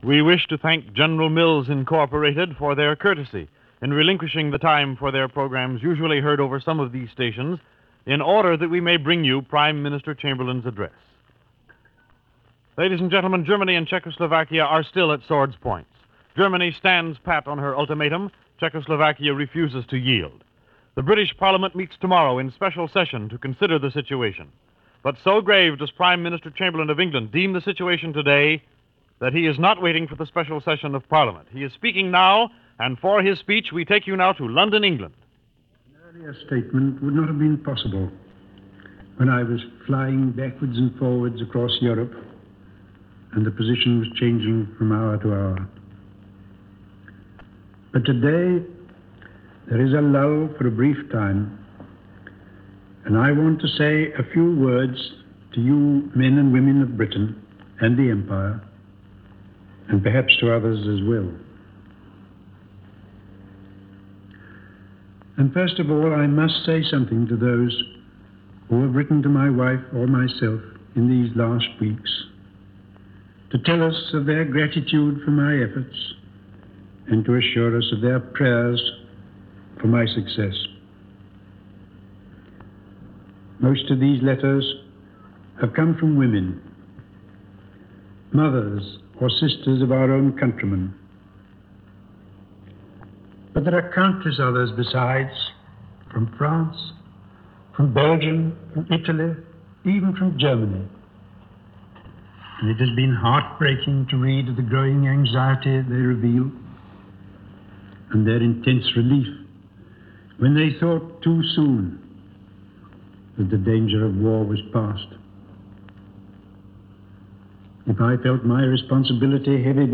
BBC Home Service – NBC Radio Blue Network